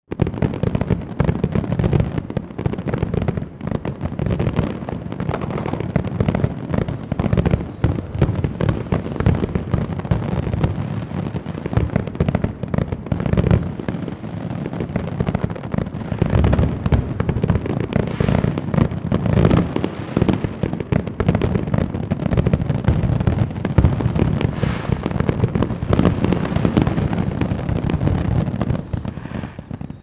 Fireworks.mp3